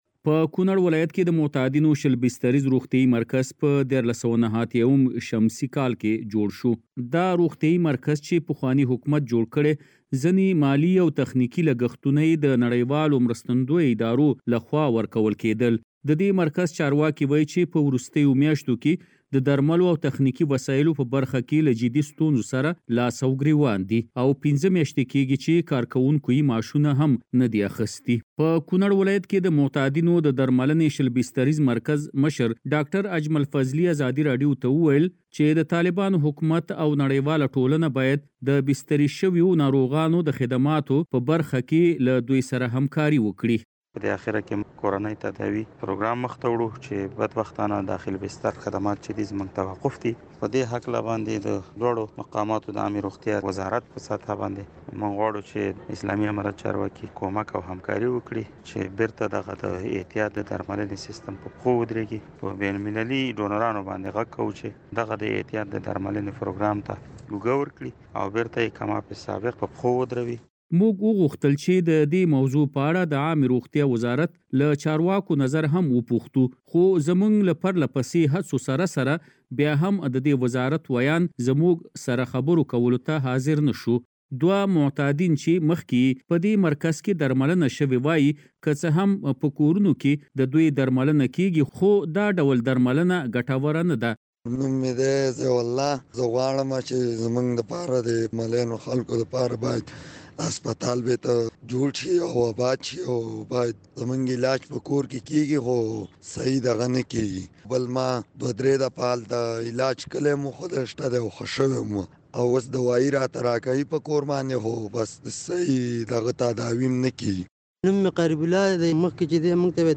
په کونړ کې د روغتیایي مرکز په اړه راپور